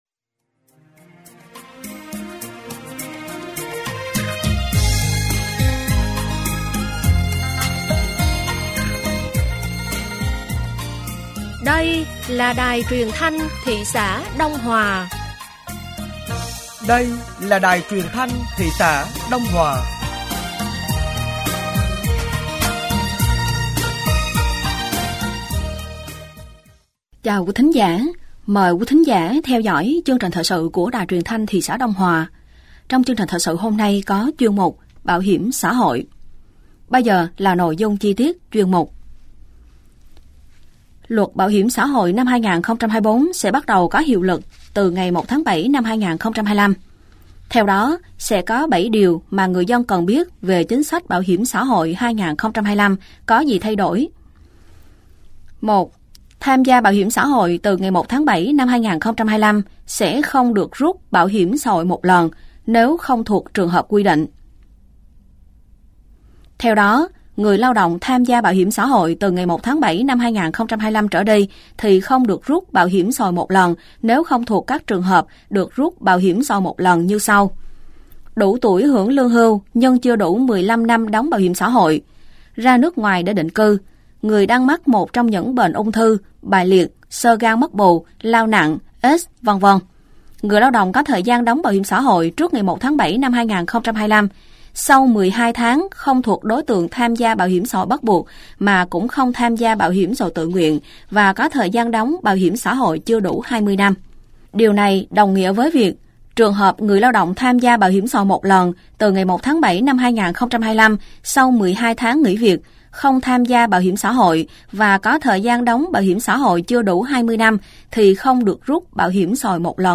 Thời sự tối ngày 15 và sáng ngày 16 tháng 3 năm 2025